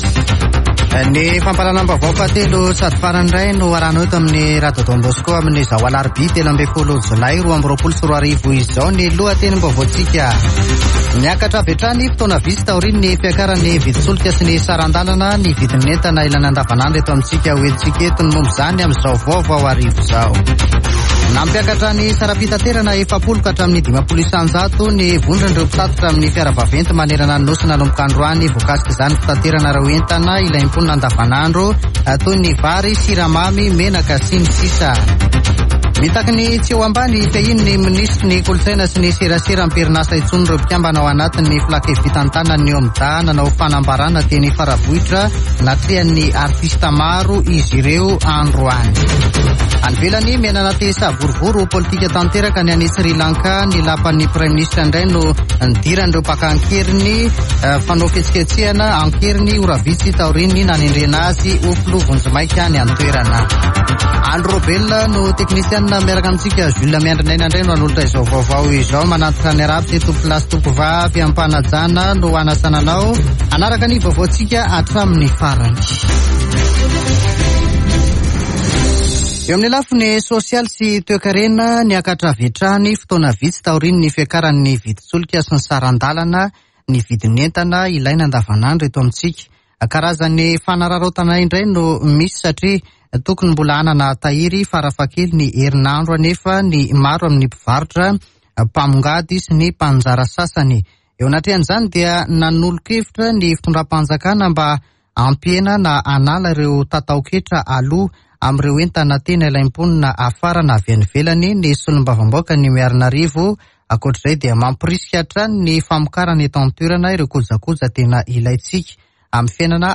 [Vaovao hariva] Alarobia 13 jolay 2022